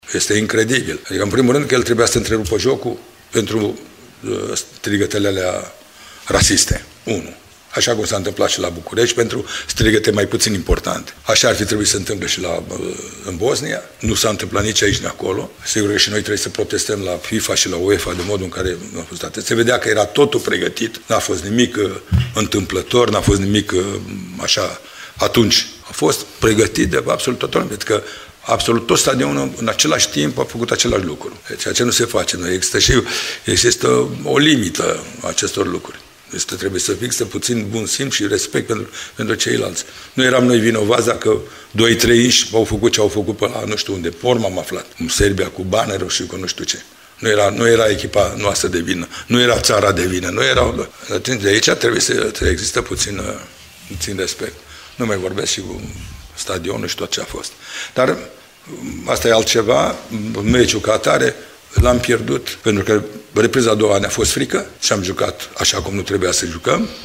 În ciuda ratării locului secund, fundașul Virgil Ghiță are în continuare încredere în această generație și vorbește și despre posibili adversari din baraj:
Gândurile selecționerului Mircea Lucescu au rămas agățate de partida de la Zenica: